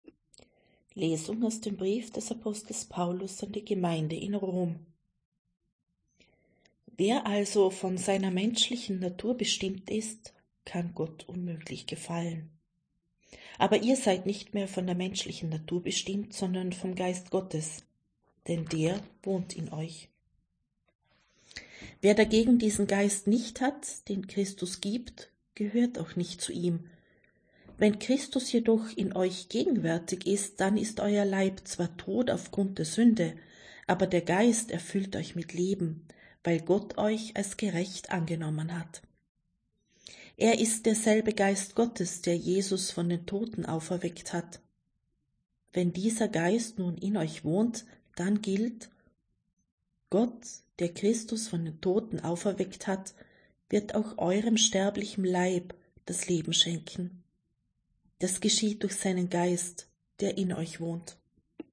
Wenn Sie den Text der 2. Lesung aus dem Brief des Apostels Paulus an die Gemeinde in Rom anhören möchten: